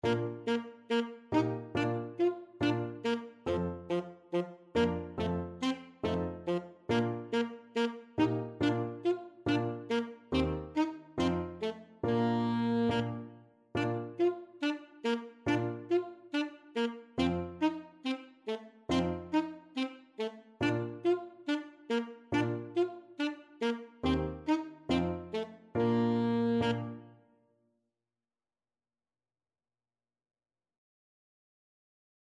Alto Saxophone
4/4 (View more 4/4 Music)
Fast = c. 140